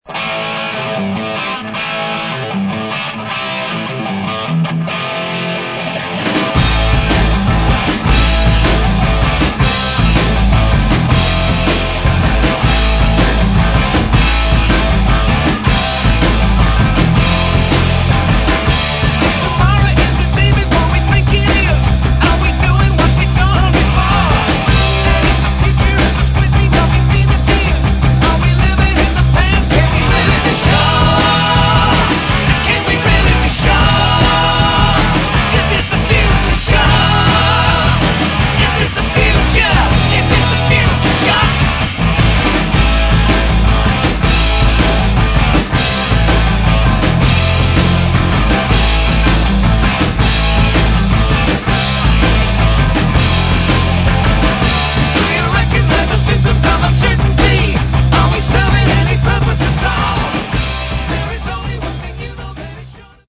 Рок
Плотный роковый бас и барабаны
клавишные
отличительные гитары